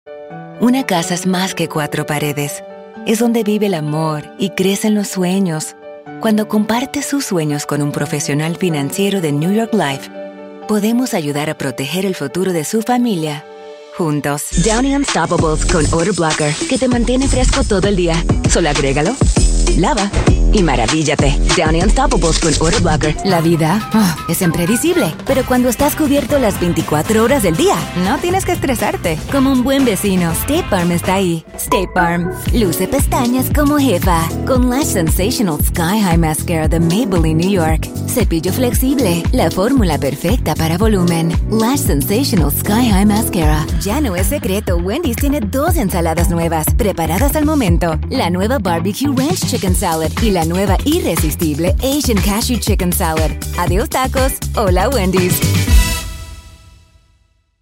accented, announcer, anti-announcer, caring, confident, conversational, cool, foreign-language, friendly, genuine, Gravitas, high-energy, informative, inspirational, middle-age, motivational, perky, professional, promo, retail, sincere, spanish-dialect, spanish-showcase, sweet, thoughtful, tough, upbeat, warm